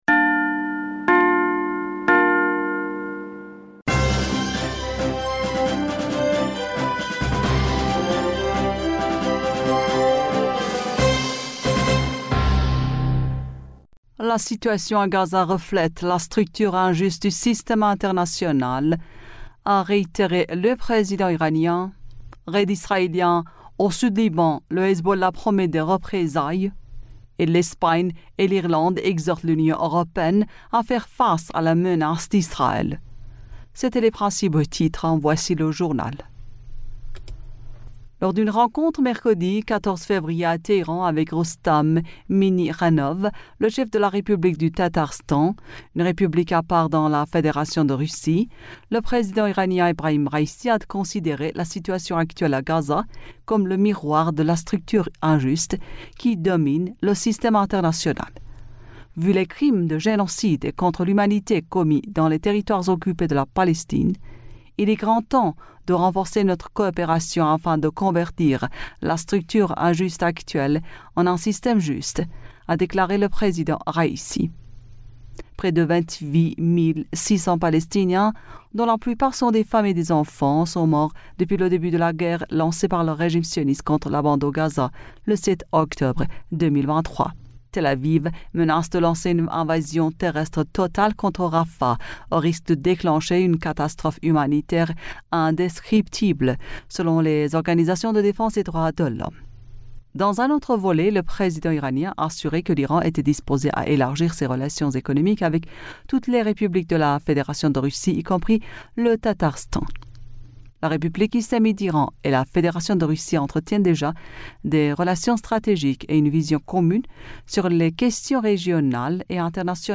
Bulletin d'information du 13 Fevrier 2024